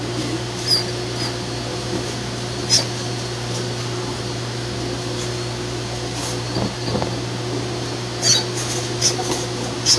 Звук мыши, которая пищит